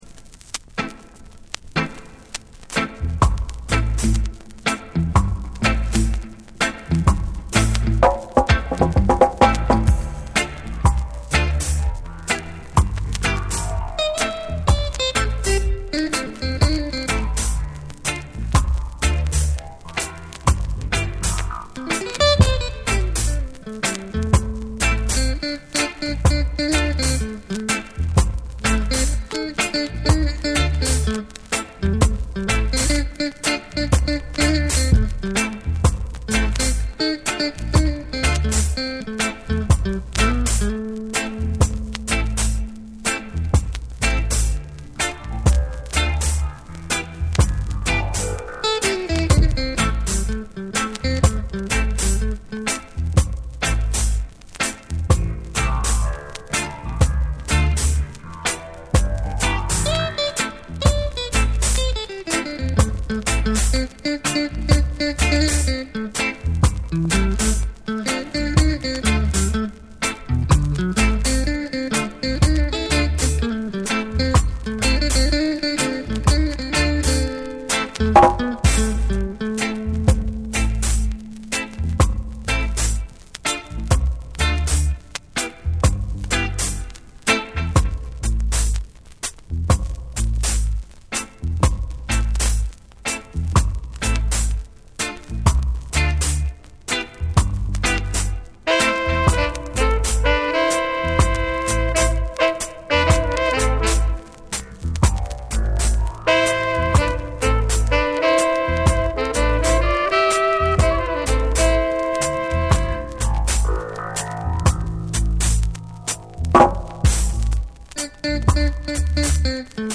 スモーキーなダブ処理と空間的なミックスで別次元の魅力を放つ好内容！
※チリノイズあり。